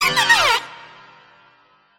Grito
900cry.mp3